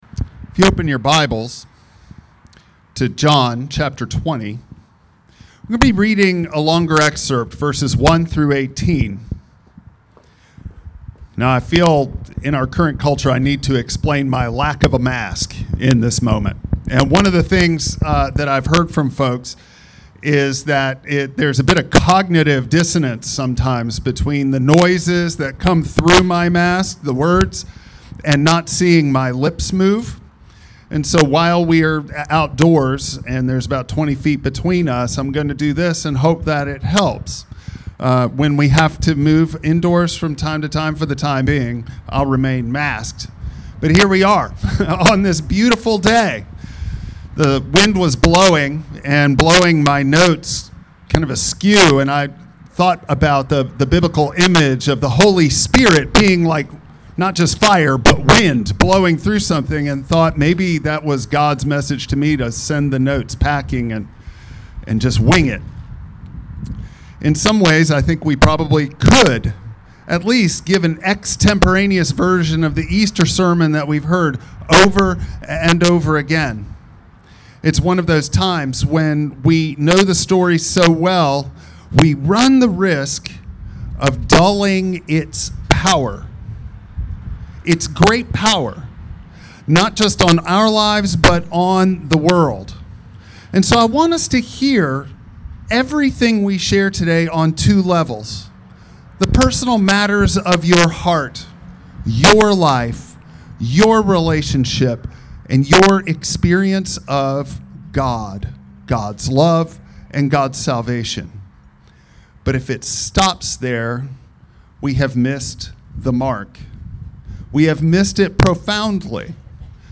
Passage: John 20:1-18 Service Type: Traditional Service